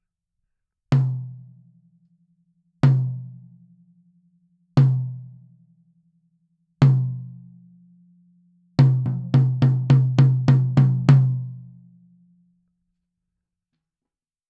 ずっしりとしたいいタムの音が録れます。
実際の録り音
タム
タム.wav